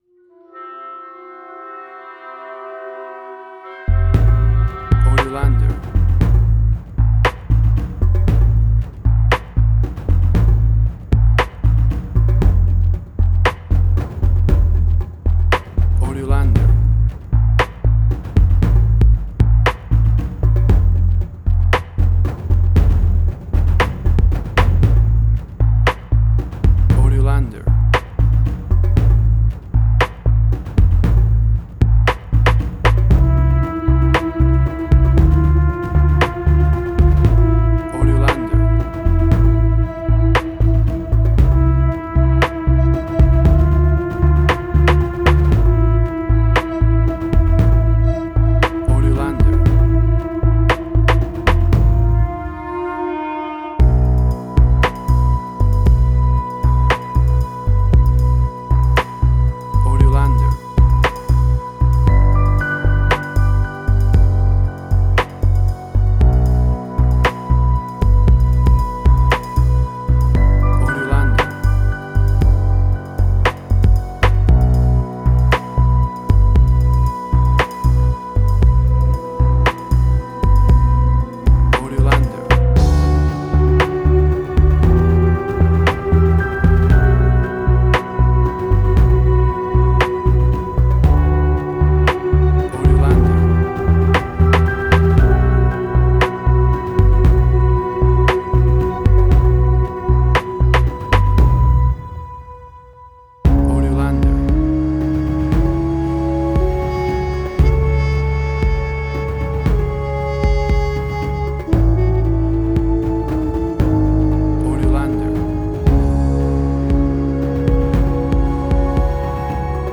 Modern Film Noir.
Tempo (BPM): 58